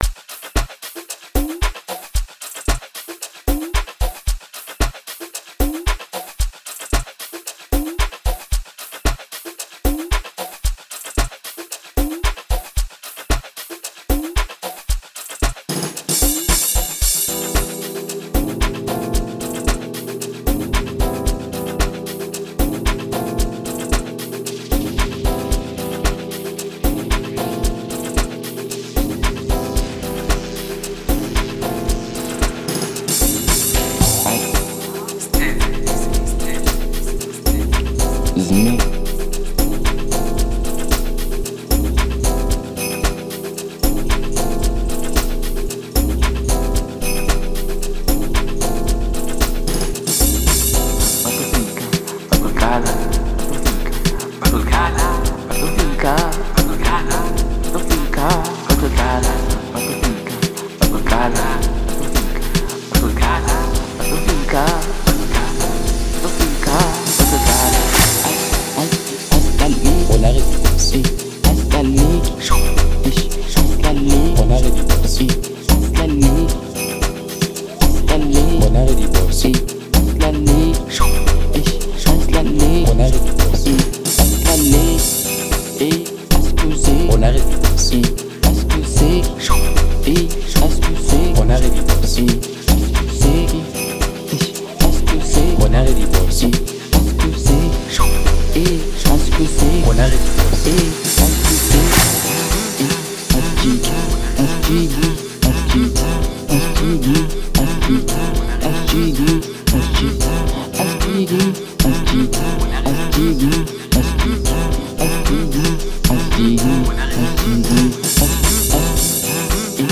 07:27 Genre : Amapiano Size